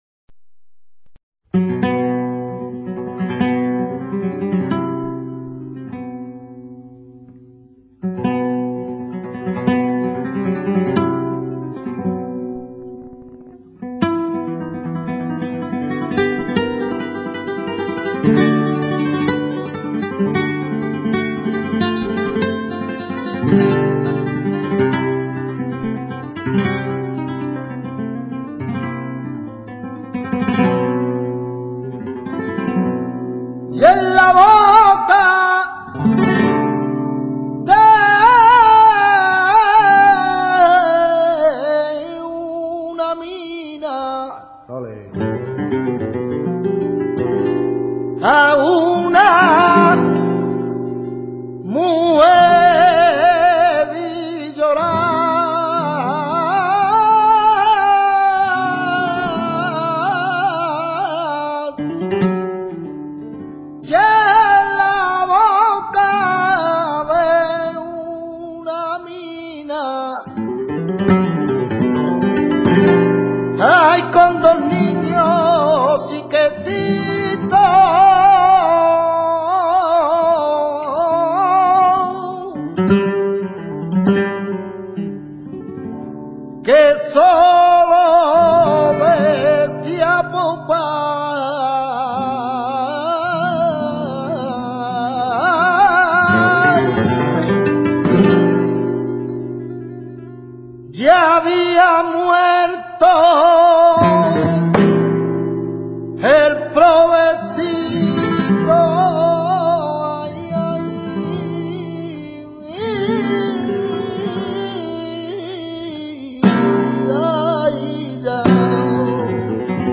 TARANTA.
Cante con copla de cuatro o cinco versos octos�labos, que al cantarla se repite uno de ellos, primero o segundo.
Es cante largo, duro, sobrio y viril, que se acompa�a a la guitarra por arriba, y, en general, no se baila, por lo que es esencialmente un cante para escuchar.
taranta.mp3